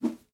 toy-move.mp3